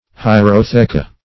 Search Result for " hierotheca" : The Collaborative International Dictionary of English v.0.48: Hierotheca \Hi`er*o*the"ca\, n.; pl.